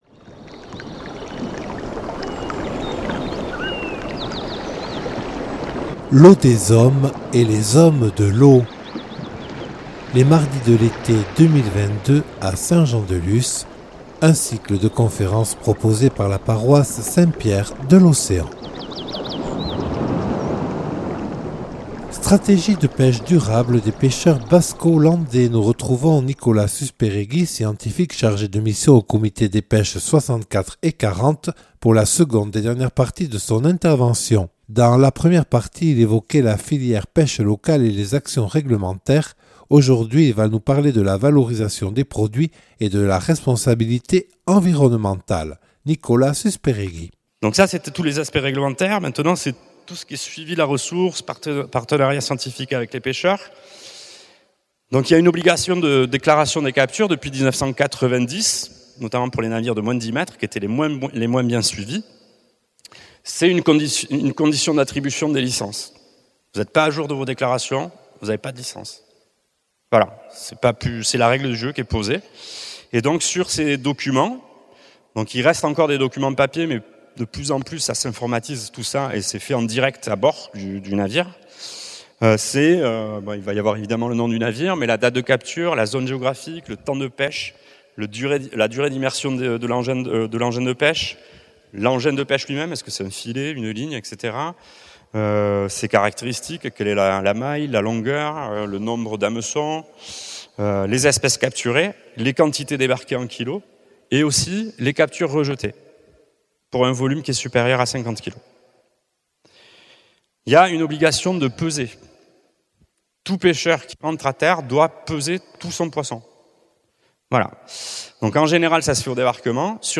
Deuxième partie de la conférence